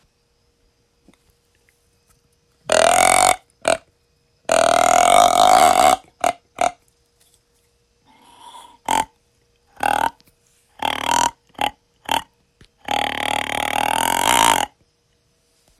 Duel Game Calls Double Back Grunt Call